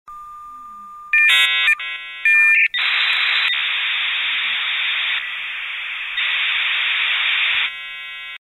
На этой странице собраны звуки, передающие атмосферу размышлений: задумчивые паузы, едва уловимые вздохи, фоновое бормотание.
Человек завис